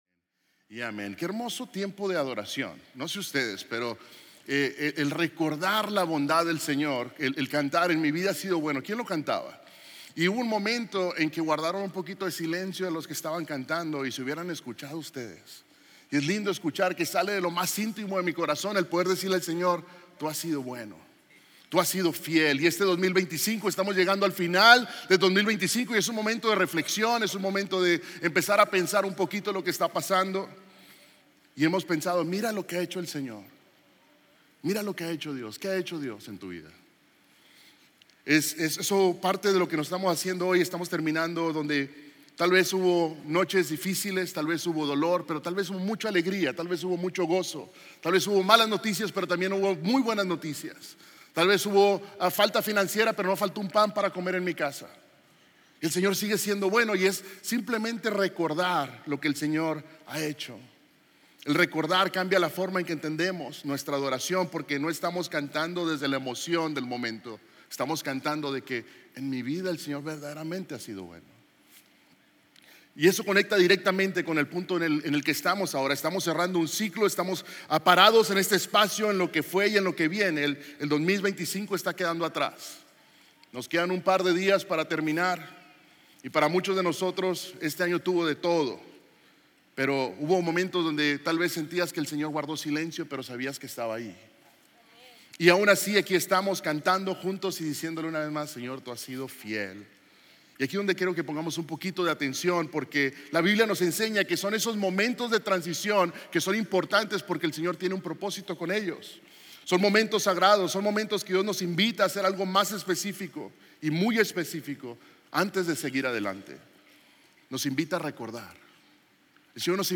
Series de Sermones – Media Player